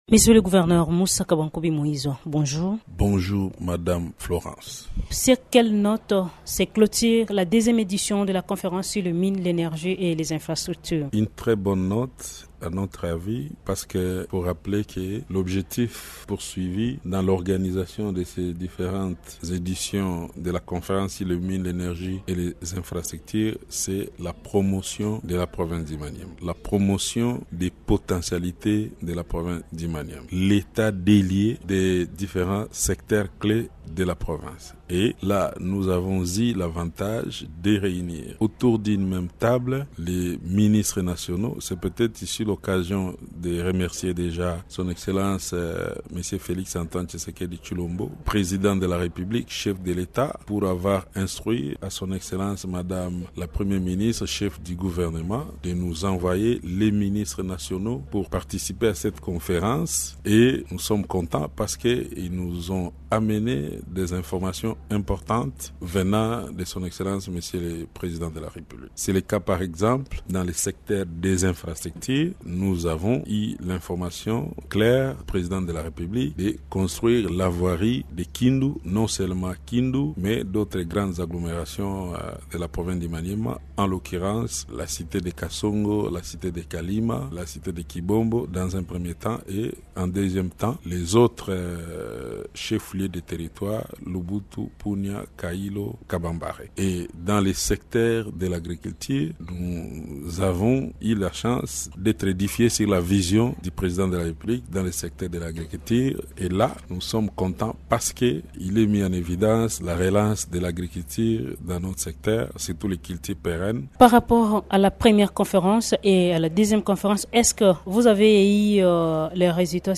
Dans un entretien avec Radio Okapi, il a souligné que l’organisation régulière de telles rencontres permet d’identifier précisément les défis ralentissant la croissance du Maniema.